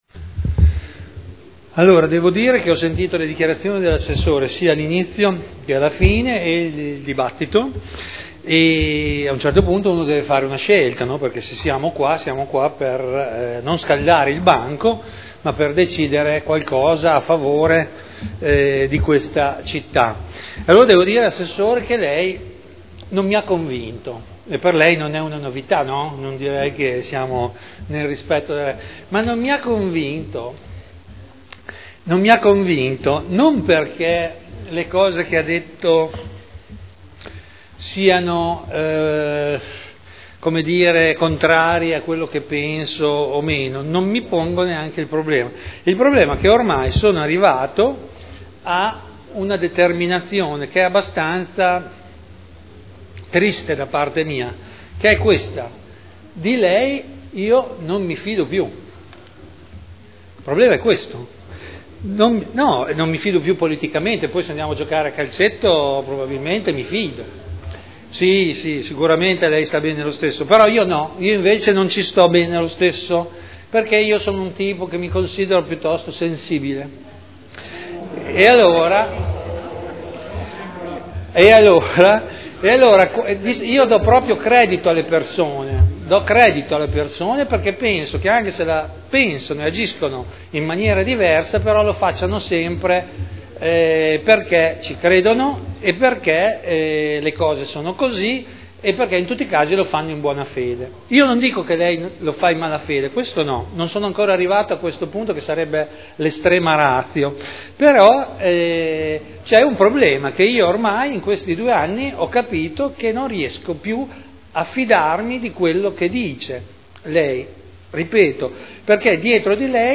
Vittorio Ballestrazzi — Sito Audio Consiglio Comunale
Seduta del 30/05/2011. Dichiarazione di voto su proposta di deliberazione: Variante al POC e al RUE relativamente a pubblici esercizi e merceologie ingombranti, aggiornamento della disciplina degli immobili con codici ISTAR-ATECO 2002 – Approvazione